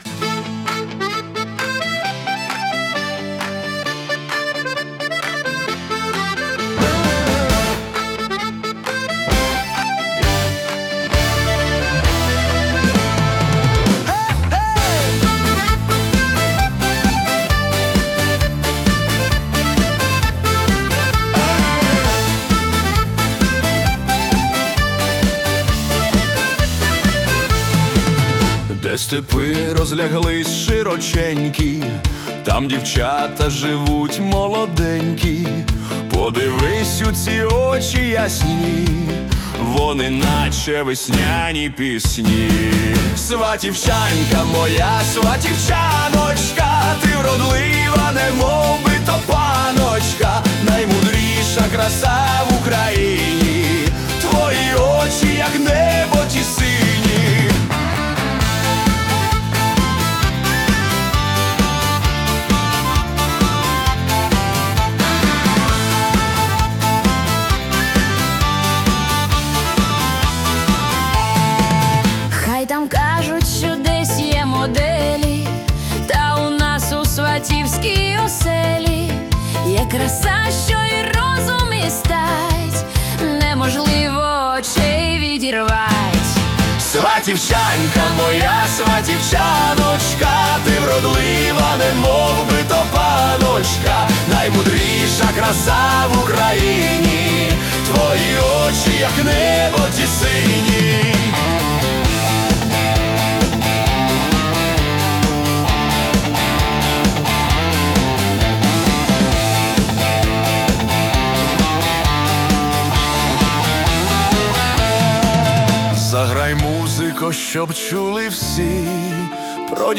Modern Folk Pop / Wedding Dance
Це справжній вибух емоцій!